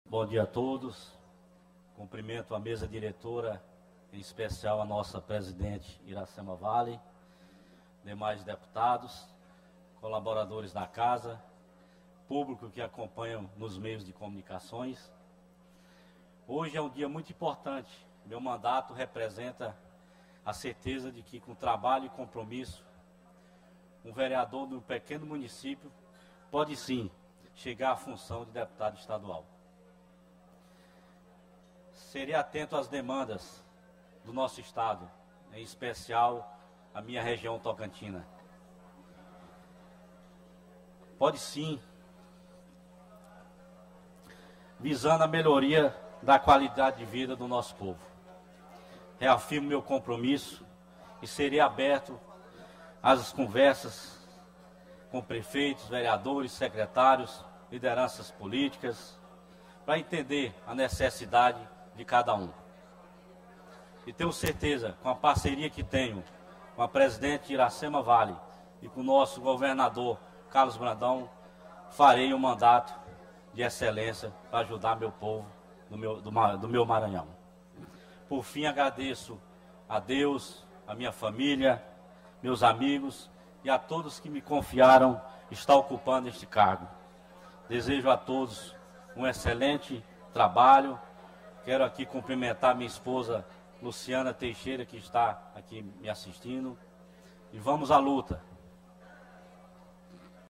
Início -> Discursos